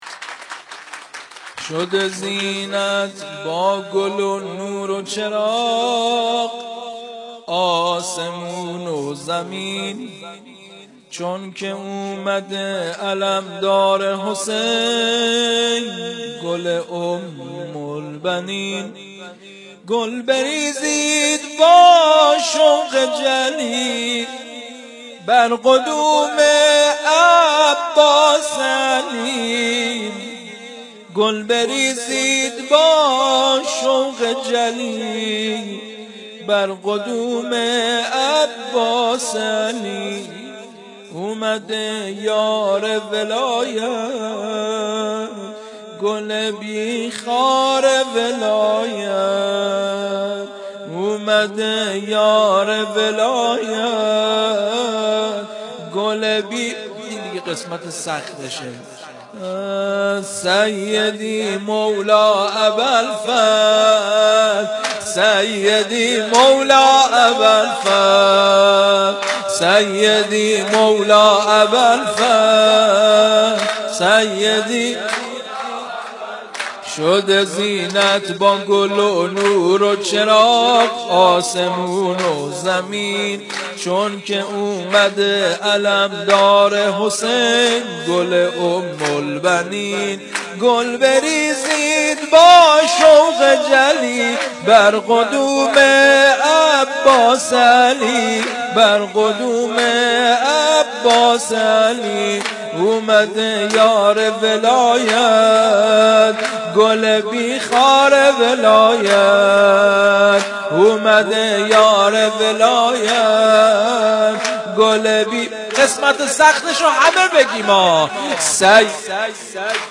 توضیحات: جشن ولادت حضرت عباس (ع)؛ سرود و توسل پایانی
روضه‌ی خانگی